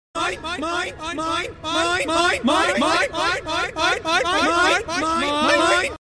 Finding nemo (Mine Mine Mine) - Meme Sound Effect